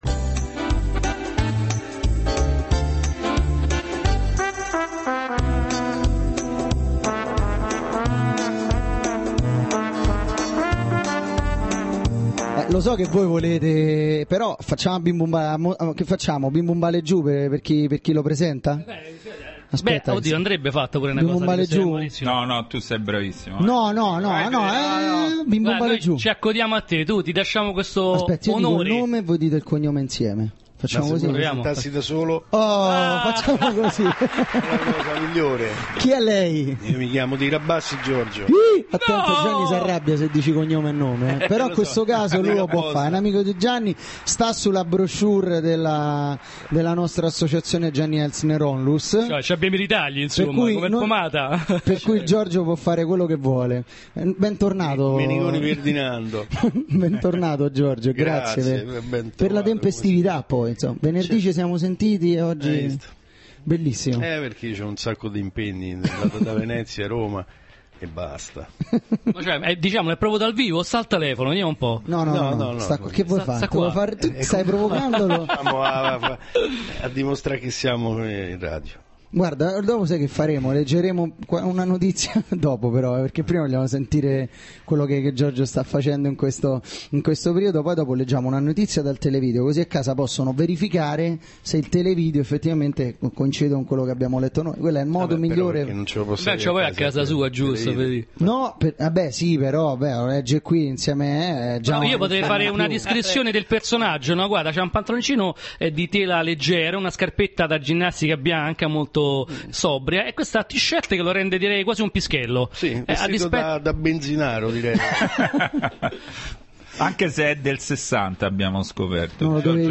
Giorgio Tirabassi ospite a Radiosei (seconda parte)
ascolta_giorgio_tirabassi_ospite_a_radiosei_secon.mp3